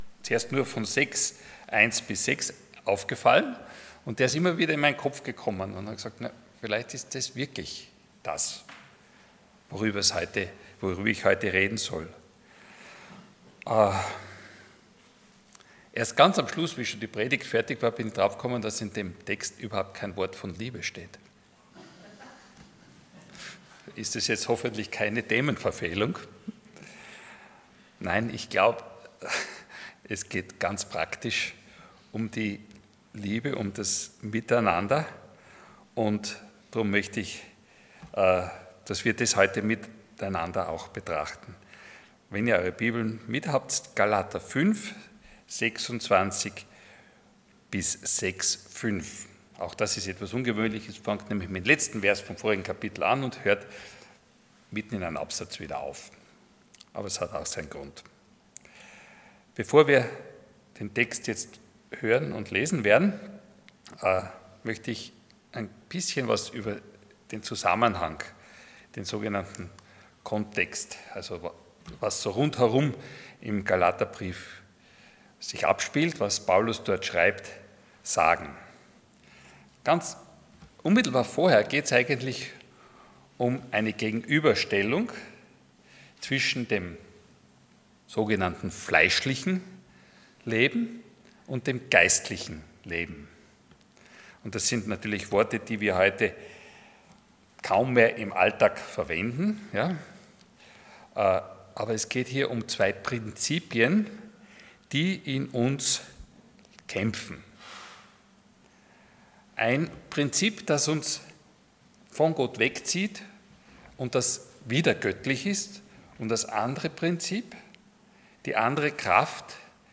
Passage: Galatians 5:26-6:5 Dienstart: Sonntag Morgen